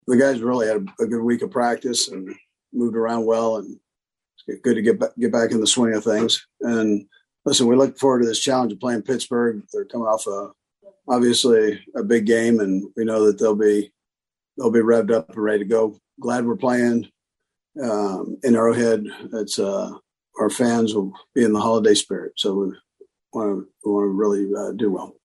Chiefs Coach Andy Reid says it has been a good week of preparation.